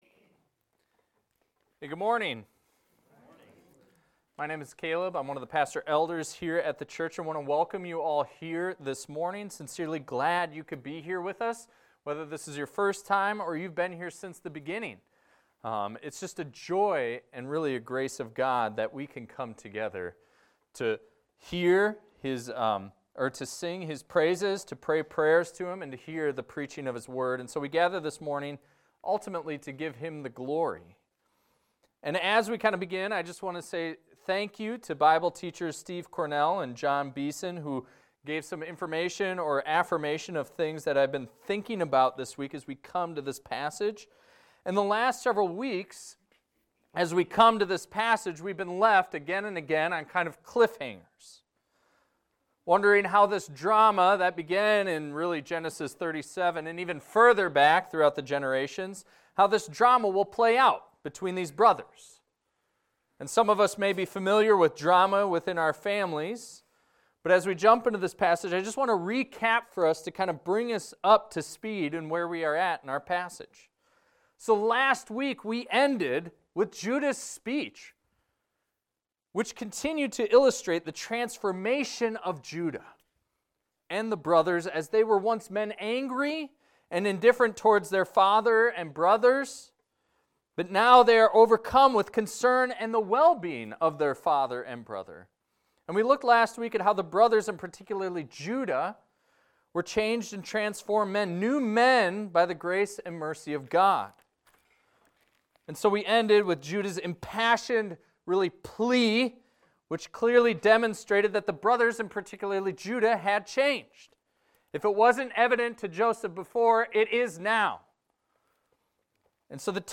This is a recording of a sermon titled, "Brothers Reunited."